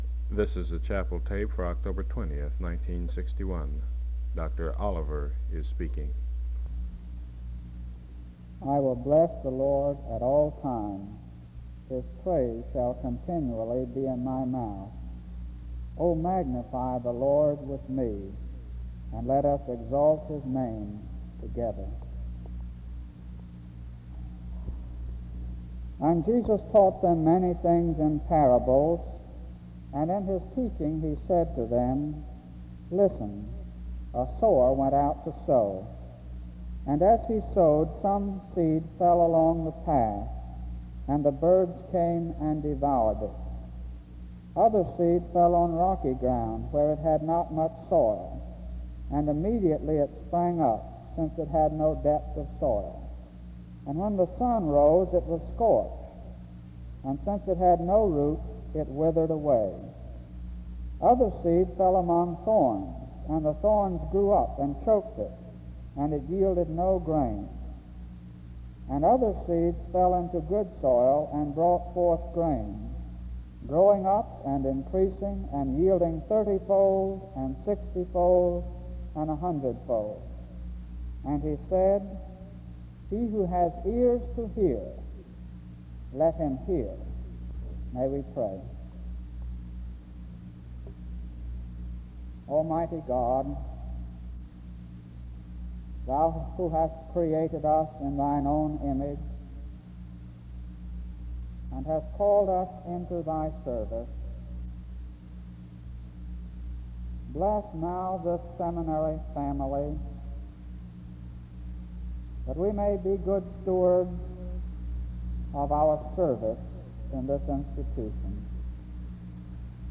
SEBTS Chapel and Special Event Recordings SEBTS Chapel and Special Event Recordings